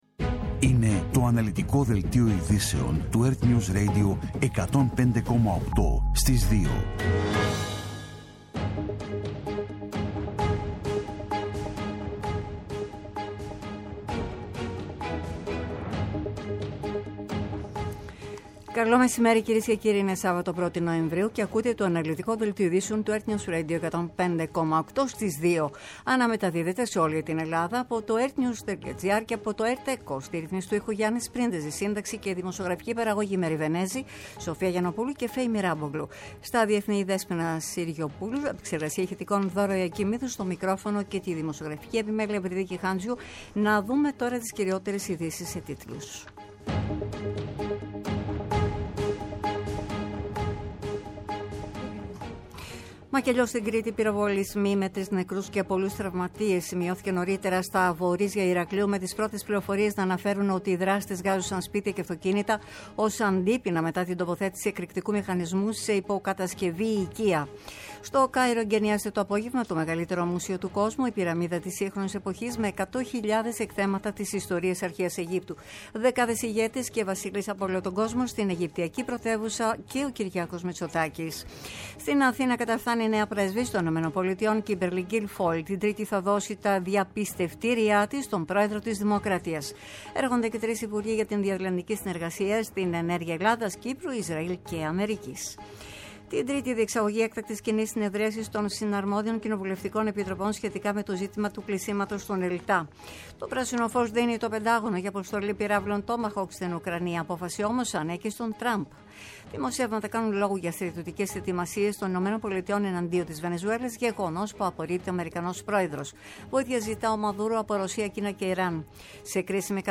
Το κεντρικό ενημερωτικό μαγκαζίνο στις 14.00.
Με το μεγαλύτερο δίκτυο ανταποκριτών σε όλη τη χώρα, αναλυτικά ρεπορτάζ και συνεντεύξεις επικαιρότητας.